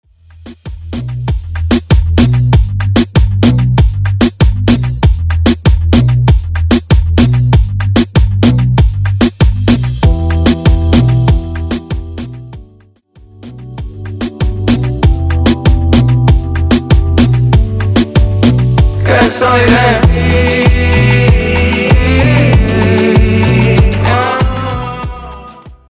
Coro Dirty